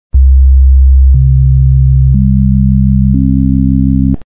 Schließlich runden Überlagerungen der ersten 4 bzw. 16 bzw. 32 Partialtöne in den Hörbeispielen